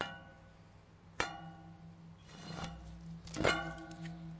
描述：充满空间感的环境吉他，带有一些海王星的琶音。
Tag: 70 bpm Chill Out Loops Guitar Electric Loops 1.15 MB wav Key : Unknown